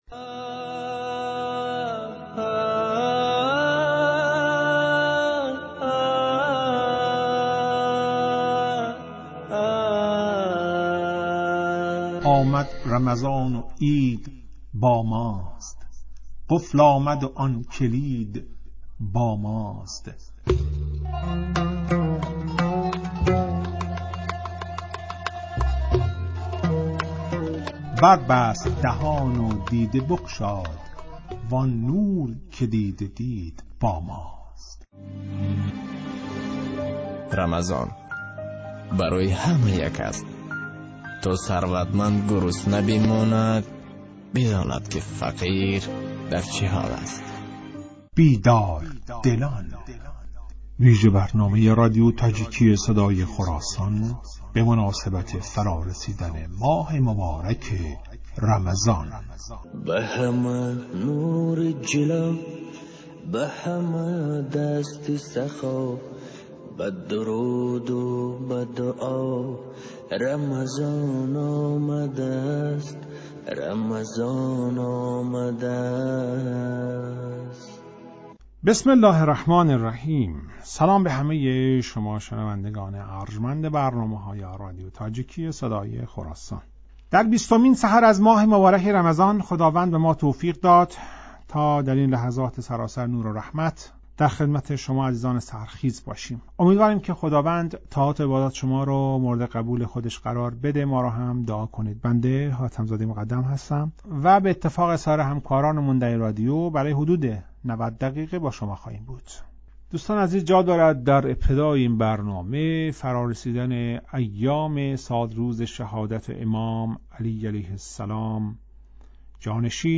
"بیدار دلان" ویژه برنامه ای است که به مناسبت ایام ماه مبارک رمضان در رادیو تاجیکی تهیه و پخش می شود.